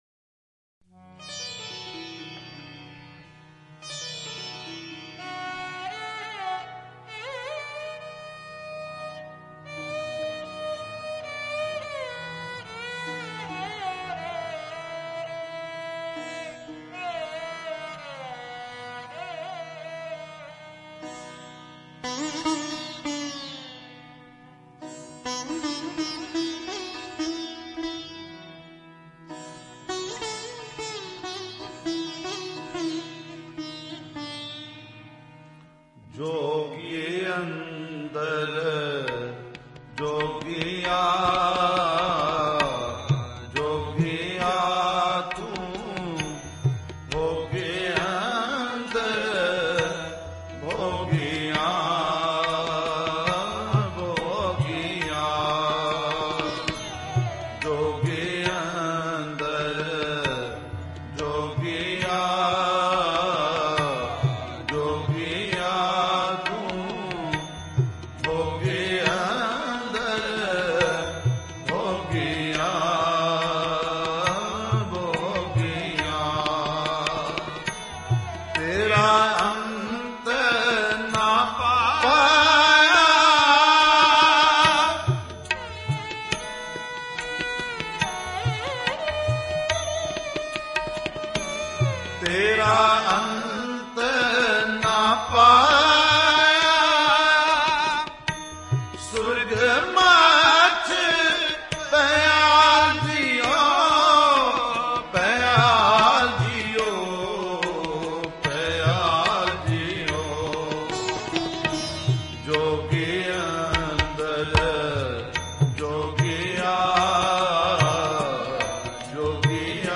Shabad Gurbani Kirtan Album Info